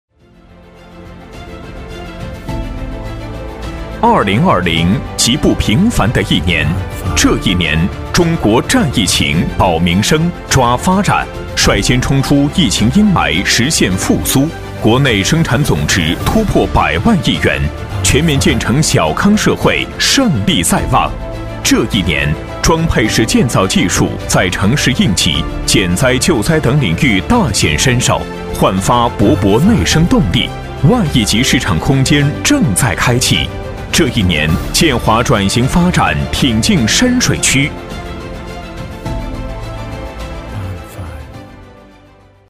男40-颁奖配音【致敬2020-建华人-大气激昂】